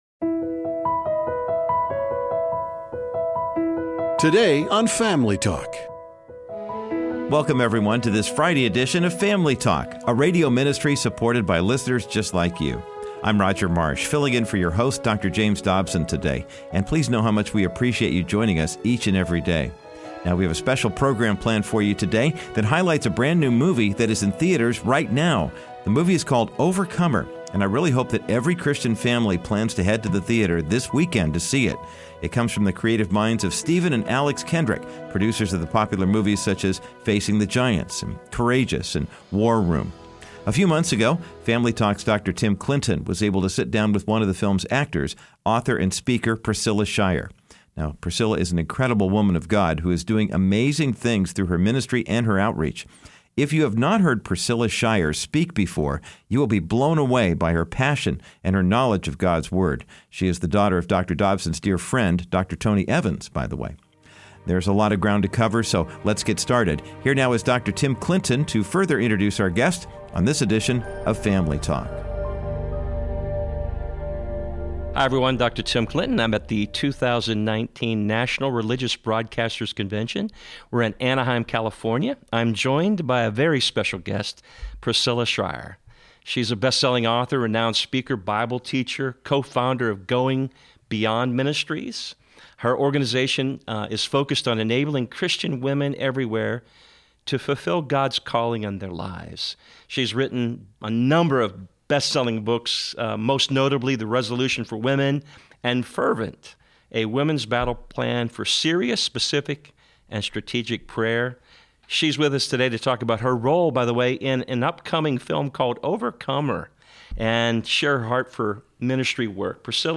Overcomer: An Exclusive Interview with Priscilla Shirer
Overcomer_An_Exclusive_Interview_with_Priscilla_Shirer.wav